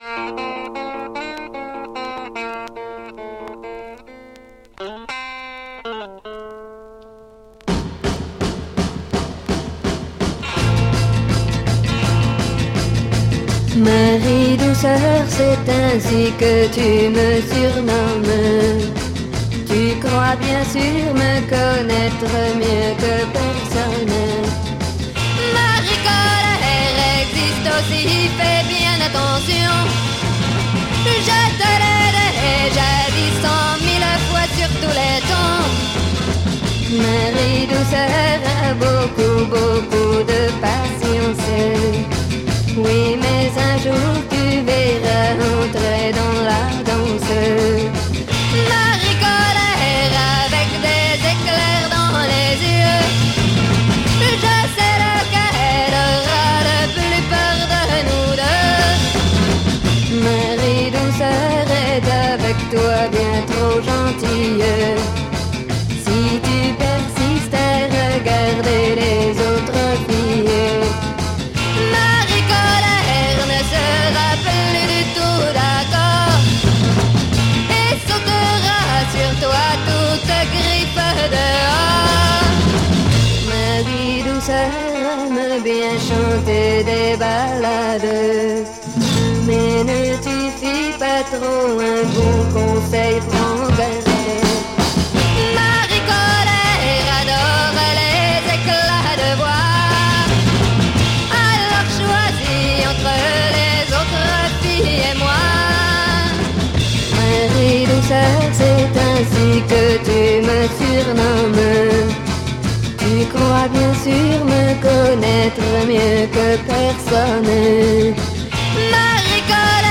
French Female Garage Folk